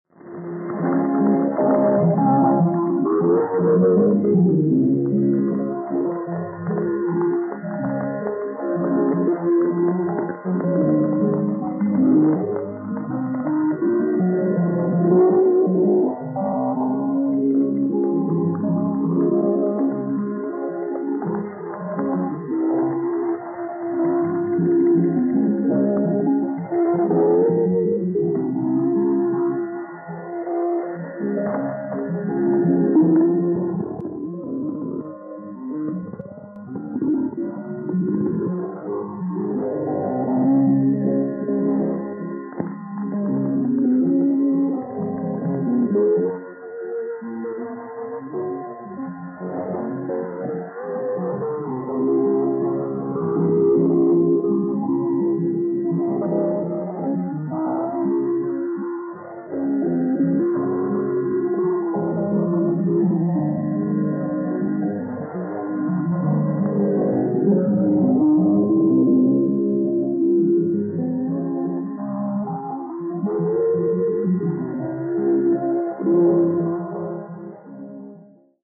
Satellit 2000 / 1978 - RRI Medan/Sumatra (4764 kHz) mit dem legendären Südsee-Schmusestück 'Love Ambon' (Song of the Coconut Islands). Kam bei den Regional-Stationen von Radio Republik Indonesia stets als verträumter Ausklang zum Sendeschluß.